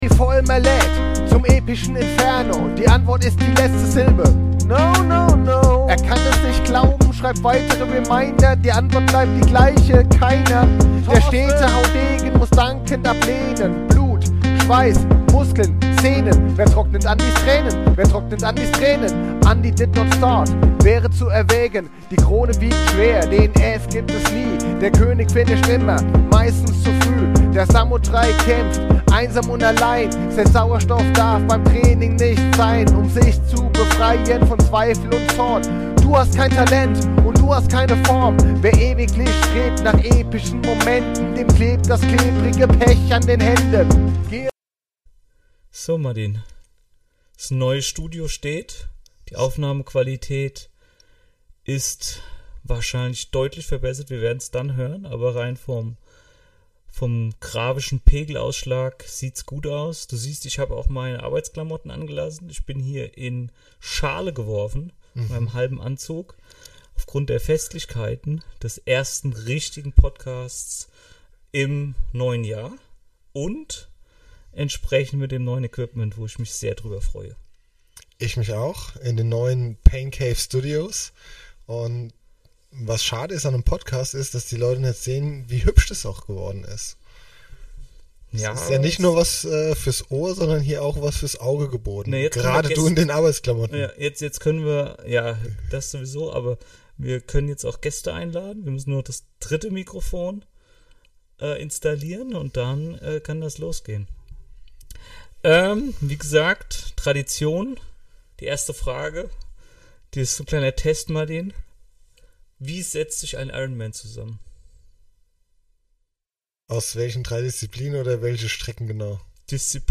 Letzte Episode Alles auf Neu 2. März 2024 Nächste Episode download Beschreibung Kapitel Teilen Abonnieren Die Pain Cave Studios gehn in die nächste Phase - der Ton soll besser werden und wir wollen professioneller werden - zumindest für unsere NFG - NanoFanGemeinde.